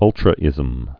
(ŭltrə-ĭzəm)